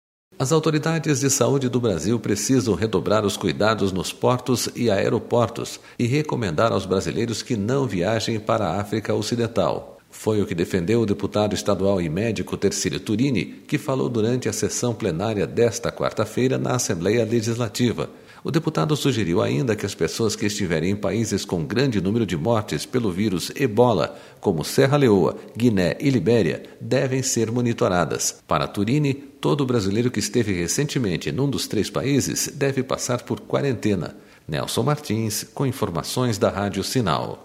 As autoridades de saúde do Brasil precisam redobrar os cuidados nos portos e aeroportos e recomendar aos brasileiros que não viajem para a África Ocidental.//Foi o que defendeu o deputado estadual e médico, Tercílio Turini, que falou durante a sessão plenária desta terça-feira, na Assembleia Legisla...